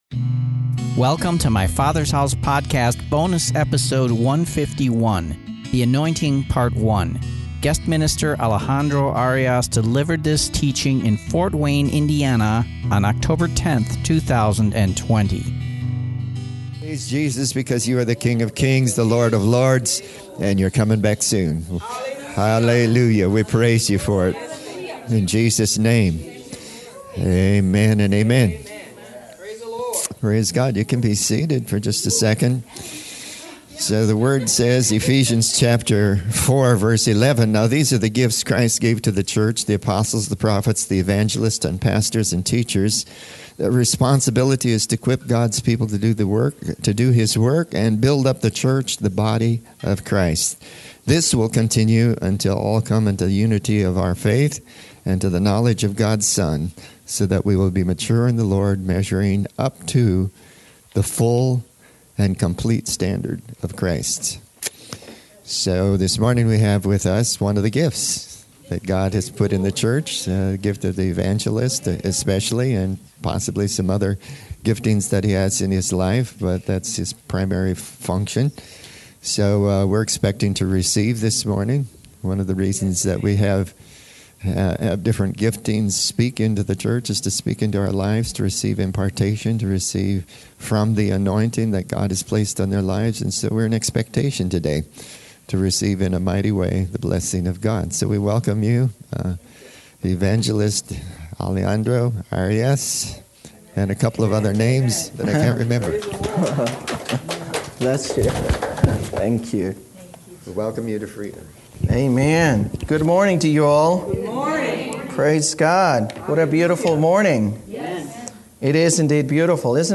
In this special leadership seminar, he shares from his heart on the subject of the anointing.